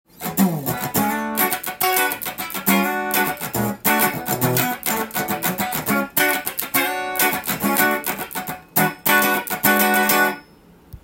からシングルコイルの細い音に変更も可能です。
シングルコイル設定で試しに弾いてみました
カッティングやコード弾きに丁度良い音がしています。
ファンキーな曲に合いそうです。